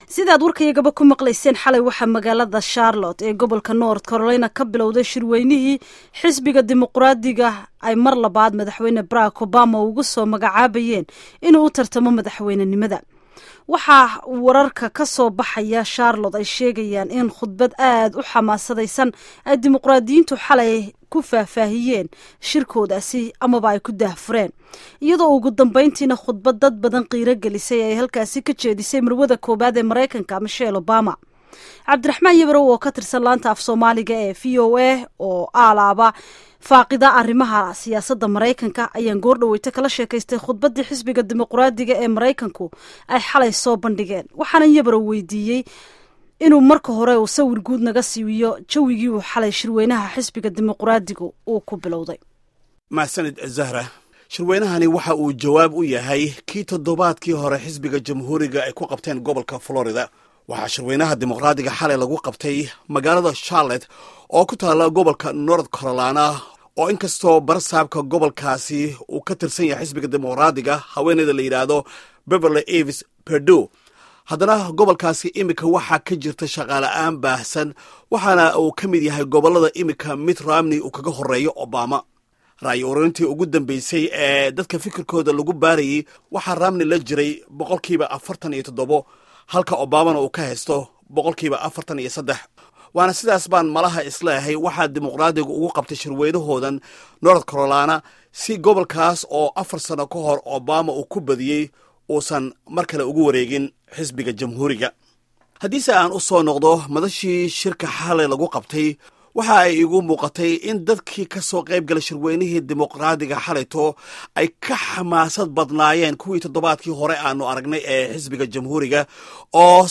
Warbixinta Shirweynaha Dimuquraadiga halkan ka dhageyso